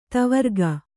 ♪ tavarga